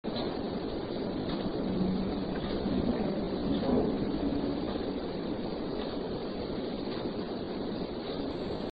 It is much deeper toned than previous messages from him.
Here are the cleaned up EVPs. They are far from “Class As” but there is someone there!